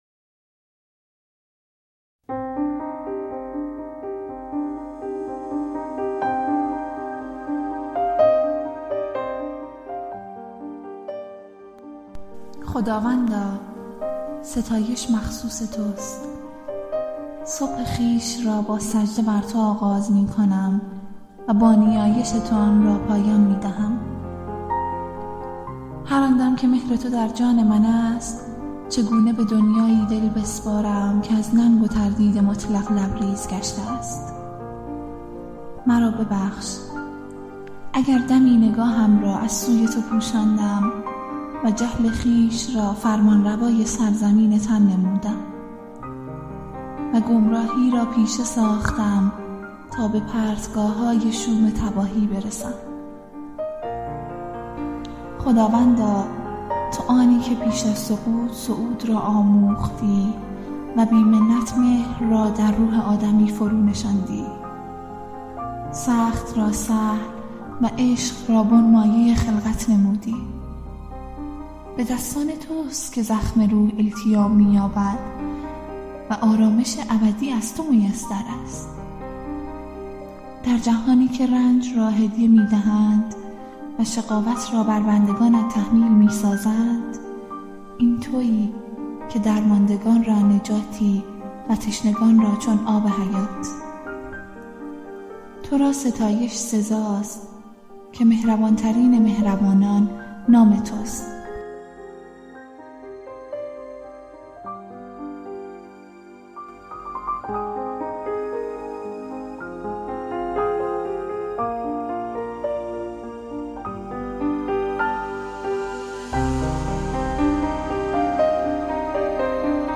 قطعه موسیقی اثر یانی کریسومالیس